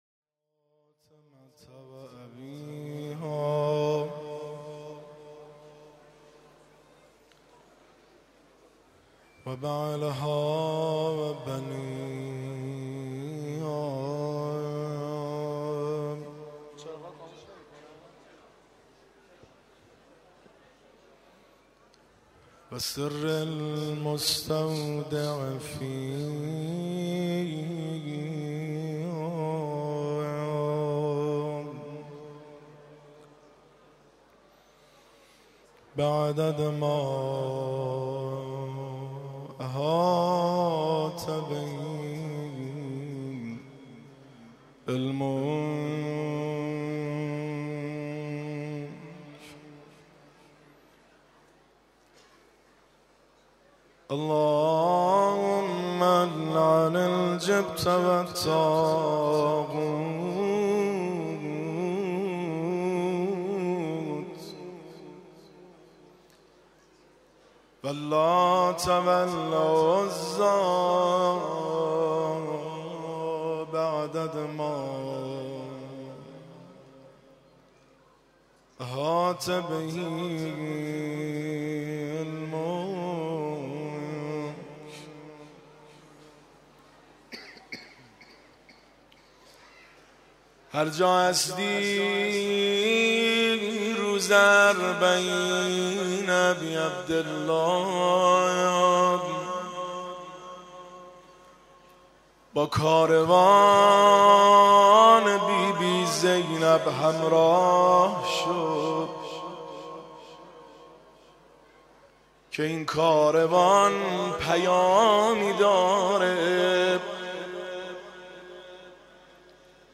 روضه زیبا و جانسوز